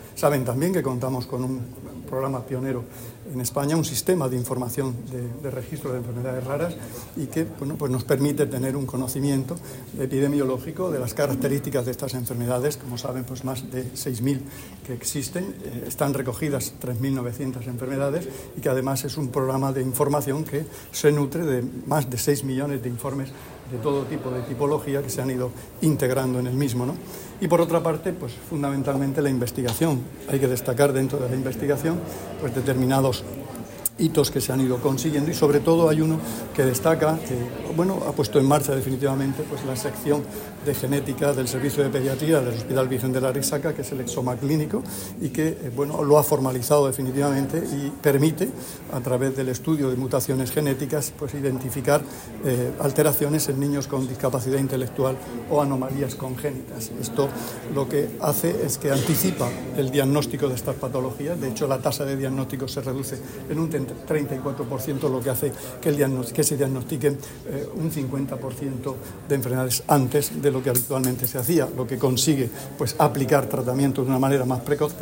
Declaraciones del consejero de Salud, Juan José Pedreño, y de la